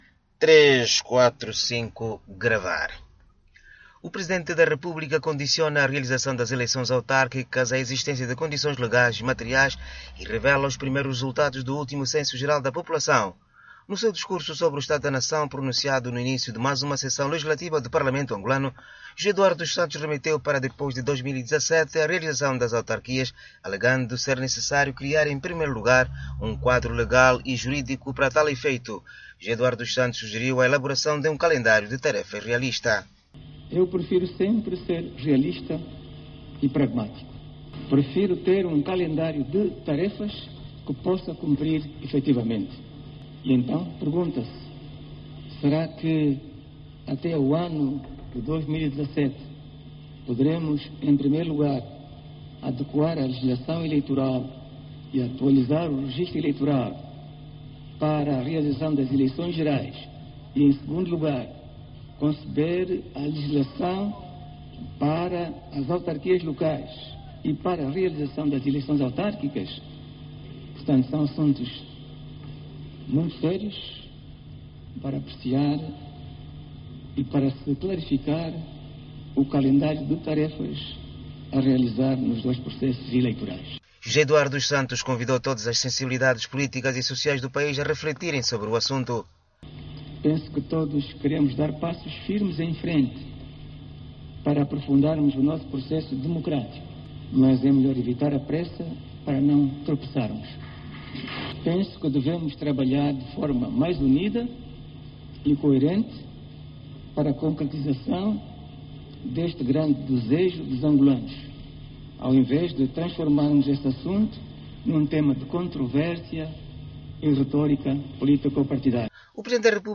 José Eduardo dos Santos no discurso sobre o Estado da Nação.